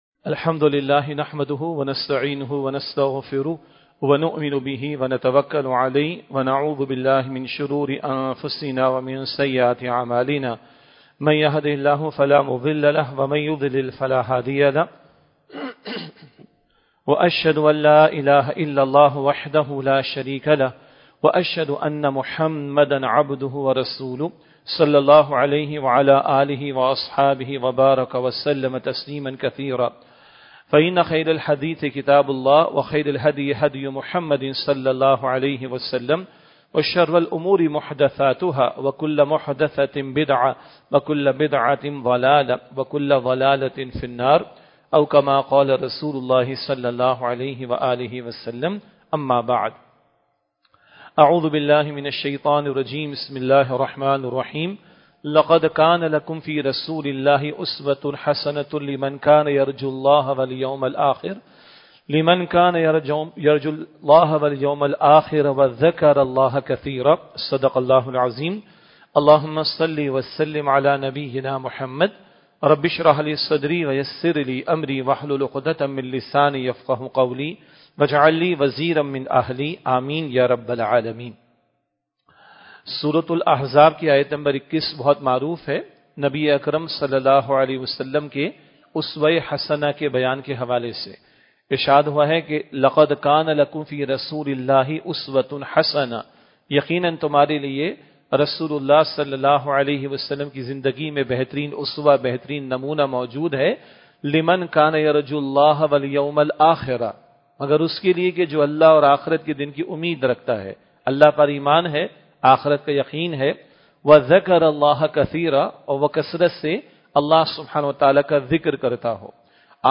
Masjid-e-Jame Al-Quran Quran Academy Defence
Khutbat-e-Jummah (Friday Sermons)